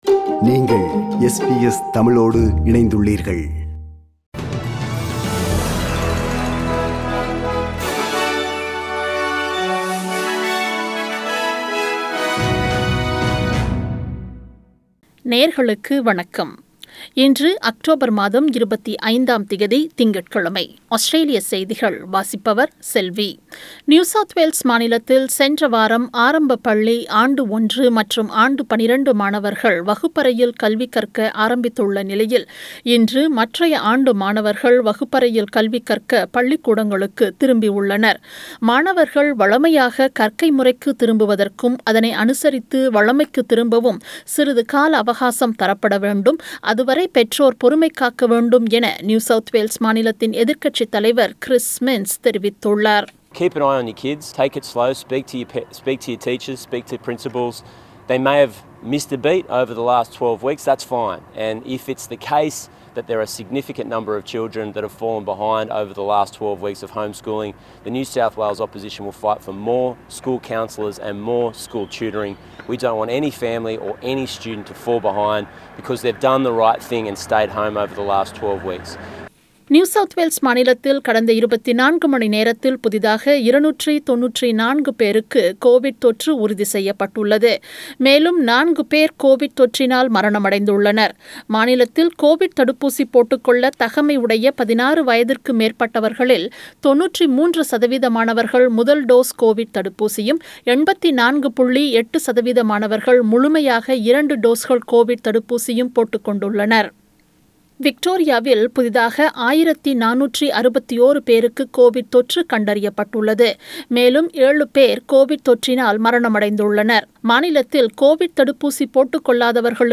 Australian News: 25October 2021 – Monday